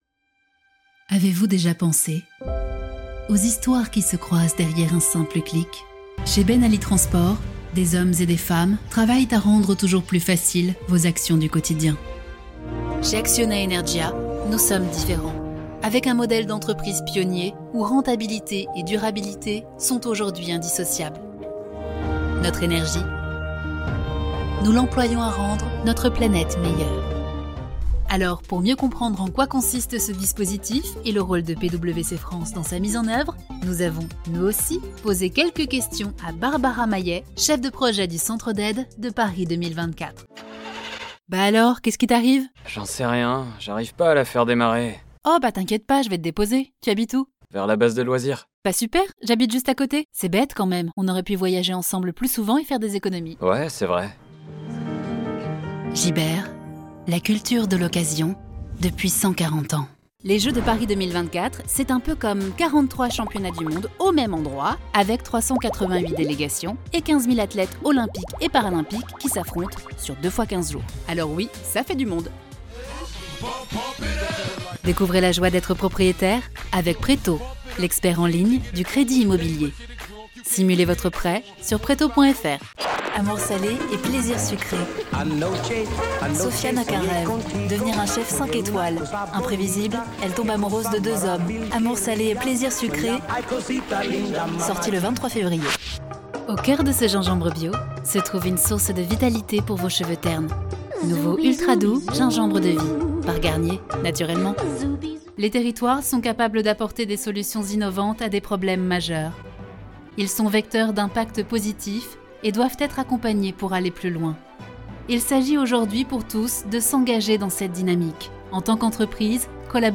Démo voix off